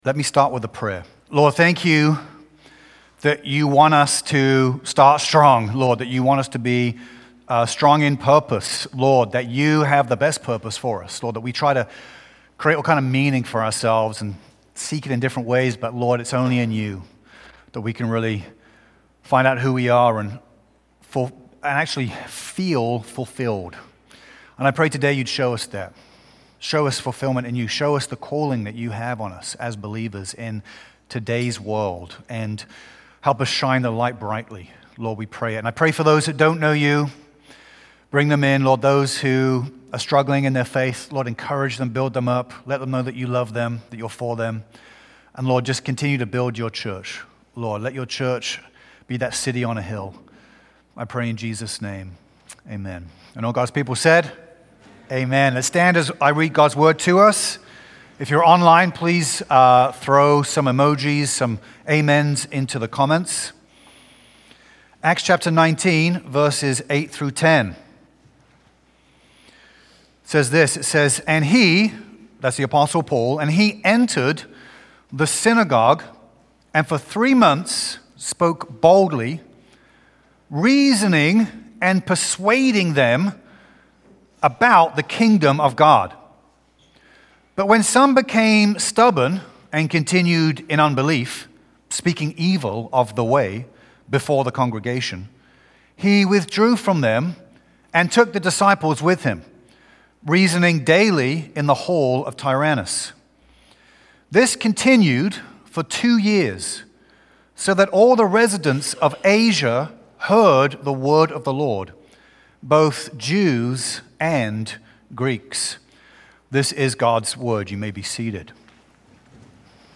A message from the series "Start Strong."